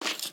Minecraft Version Minecraft Version snapshot Latest Release | Latest Snapshot snapshot / assets / minecraft / sounds / mob / stray / step2.ogg Compare With Compare With Latest Release | Latest Snapshot
step2.ogg